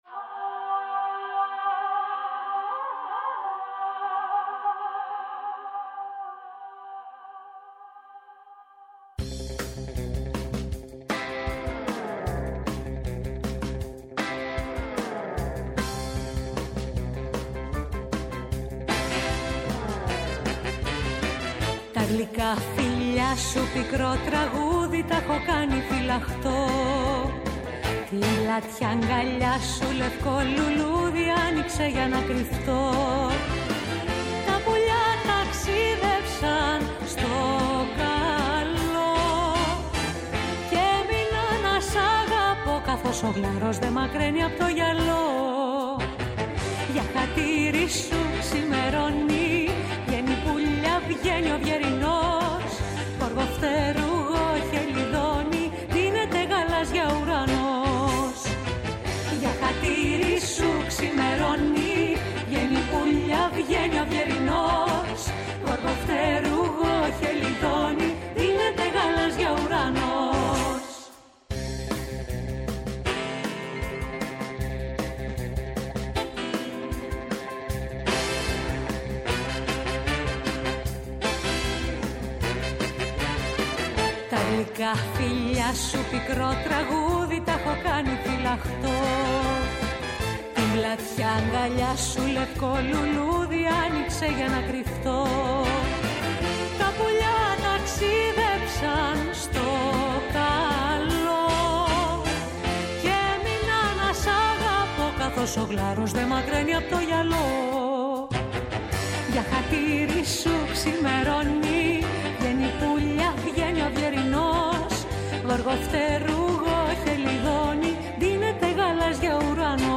Απόψε καλεσμένοι στο στούντιο